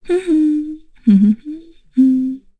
FreyB-Vox_Hum.wav